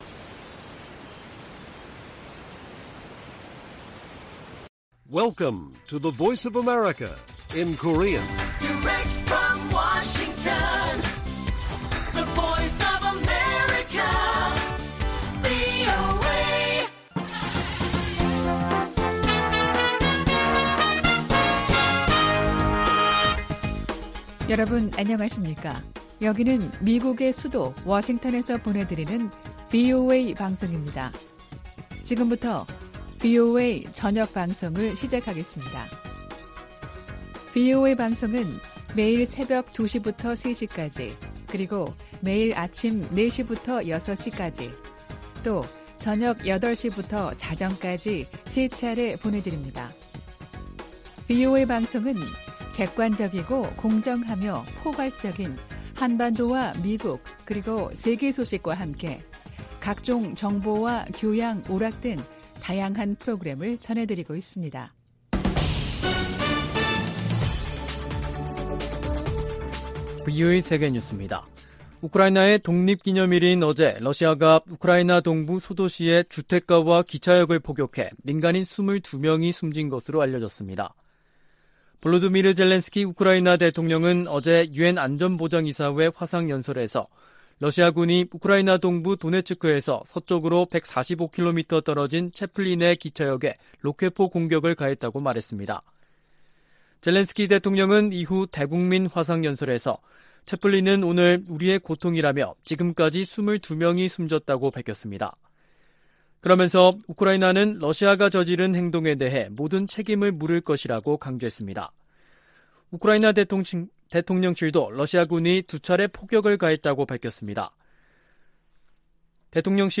VOA 한국어 간판 뉴스 프로그램 '뉴스 투데이', 2022년 8월 25일 1부 방송입니다. 일각에서 거론되는 ‘미북 관계 정상화’ 방안과 관련해 미국 정부는 ‘동맹과의 긴밀한 협력’이 중요하다고 밝혔습니다. 주한미군의 사드는 한국을 보호하기 위한 방어체계라고 미 국방부가 강조했습니다. 약 두 달 앞으로 다가온 미국 중간선거에서, 한반도 문제에 적극 개입해 온 주요 의원들이 재선될 것으로 관측됩니다.